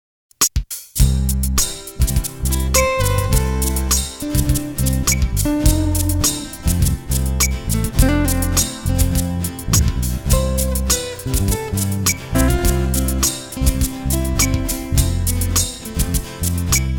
Músico, Arreglista, Guitarrista y Compositor